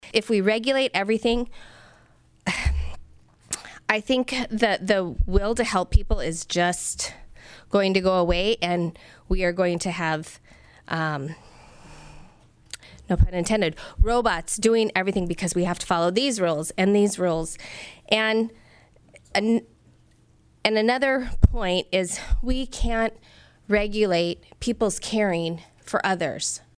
District 3 state Rep. Brandei Schaefbauer, R-Aberdeen said this is too much government regulation…